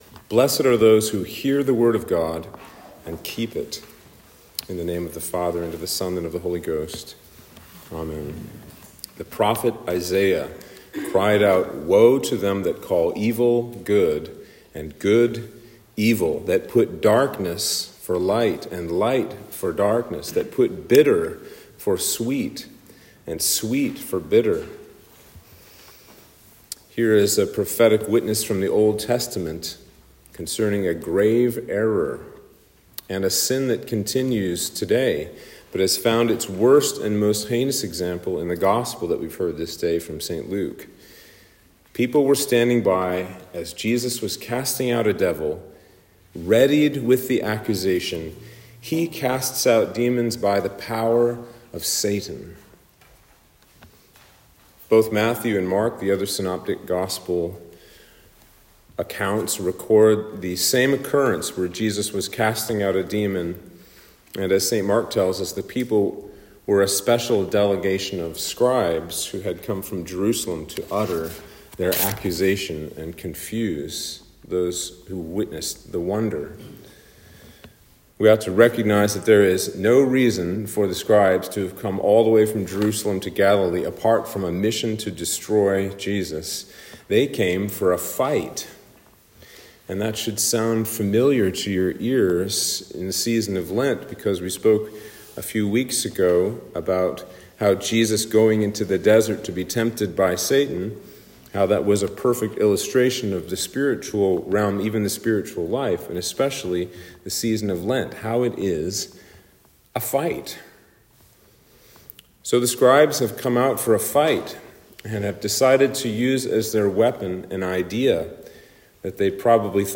Sermon for Lent 3